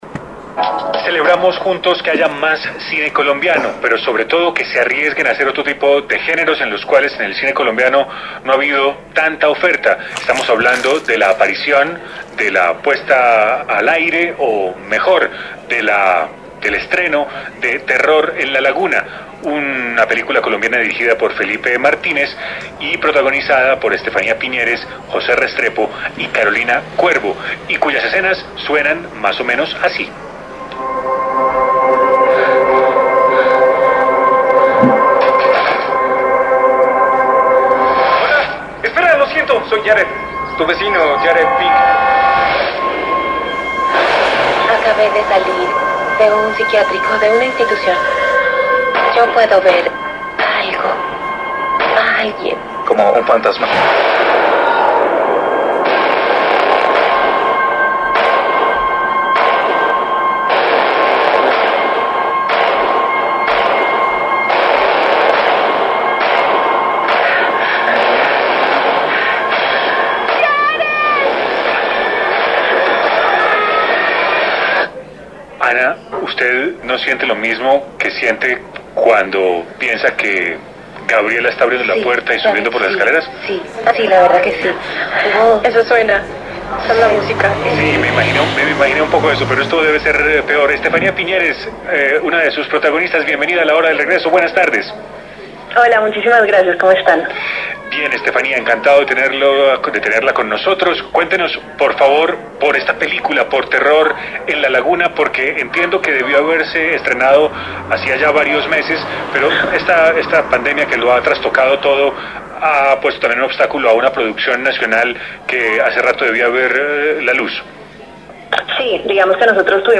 En entrevista con La Hora del Regreso